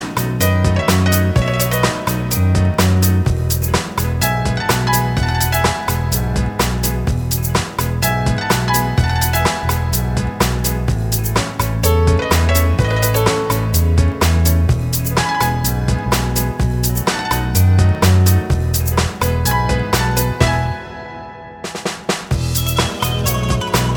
Minus Guitars Pop (1960s) 3:20 Buy £1.50